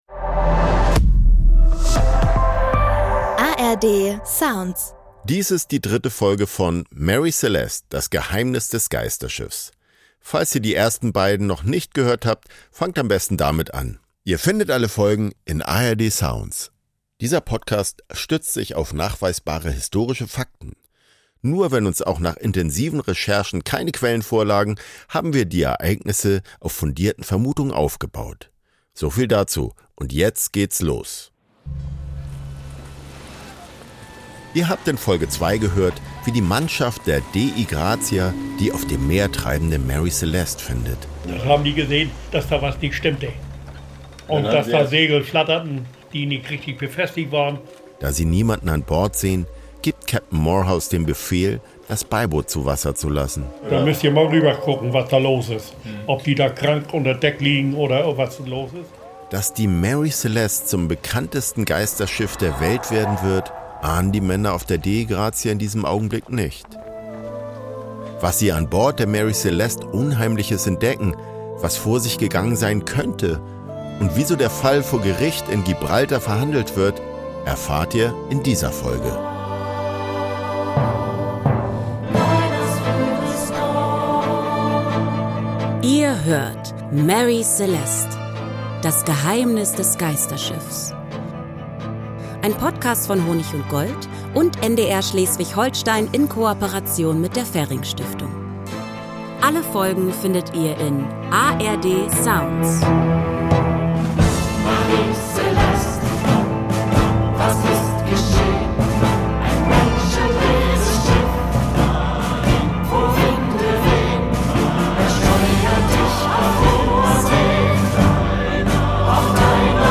In Gibraltar startet sofort eine offizielle Untersuchung. Diese Reportage verfolgt die Ermittlungen und zeigt, wie rasch Schuldzuweisungen entstehen, wenn Informationen fehlen. Vorurteile und wirtschaftliche Interessen verzerren den Blick auf den Fall.